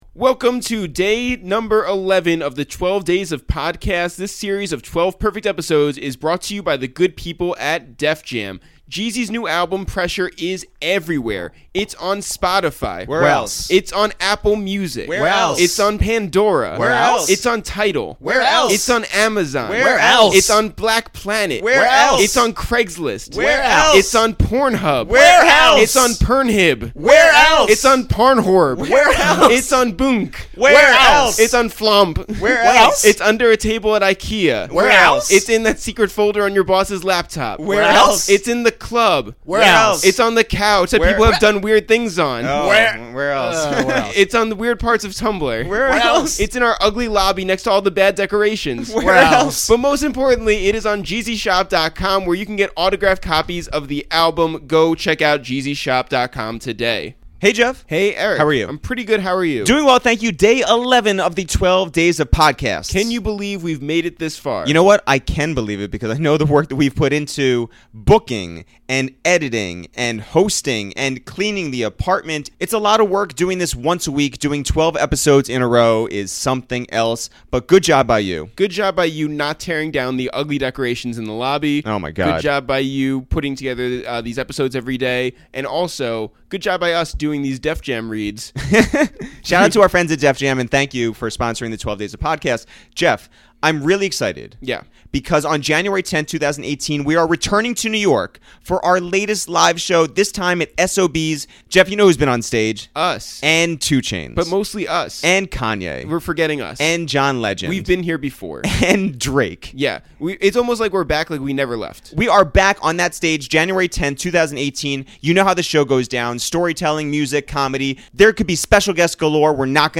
In our annual wide-ranging, hilarious and heartfelt year-end conversation, we discuss the tragic passing and ultimate legacy of Reggie Osse bka Combat Jack, Peter's current attitude toward radio competitors Mike Francesa, Charlamagne Tha God, Craig Carton and Chris Carlin, the wild insult of someone claiming Peter has "Trump hands," Black Thought's epic freestyle on Funkmaster Flex's show, meeting his hero Bobby "The Brain"